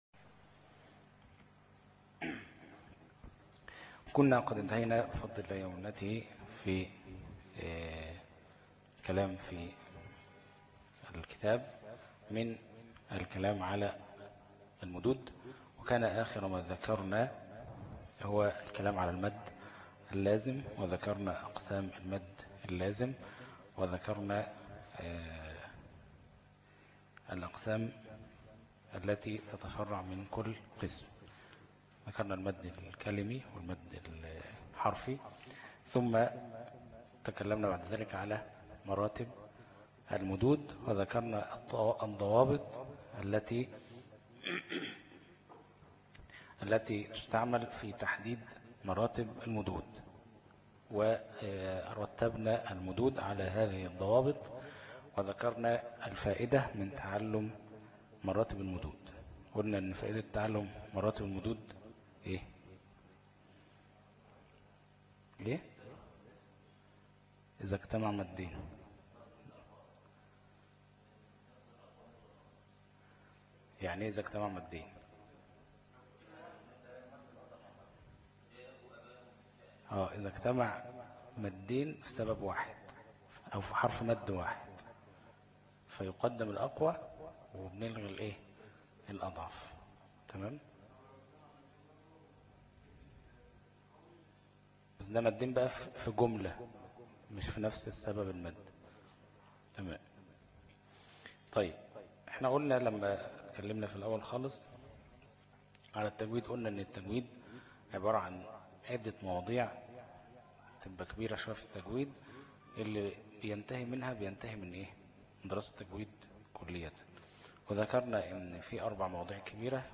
دروس التجويد 17 - الفرقة التمهيدية - الشيخ أبو إسحاق الحويني